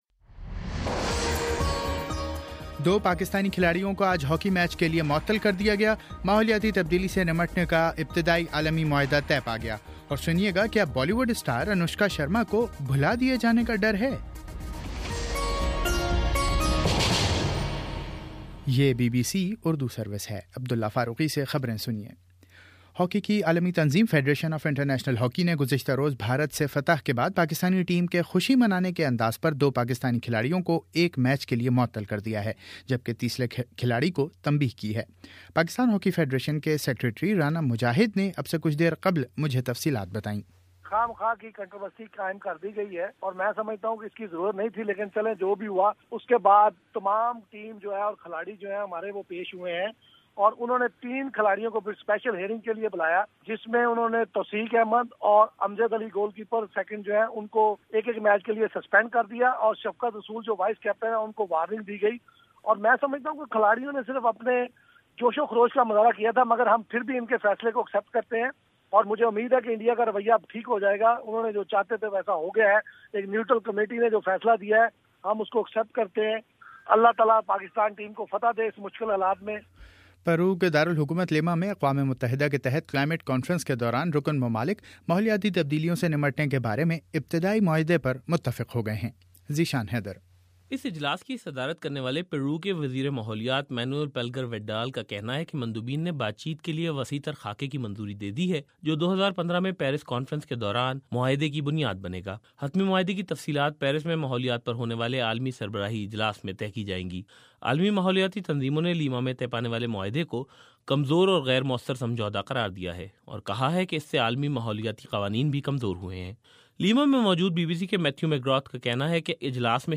دسمبر14: شام سات بجے کا نیوز بُلیٹن